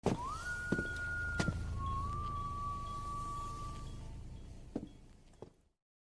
No whistle is more scary, sound effects free download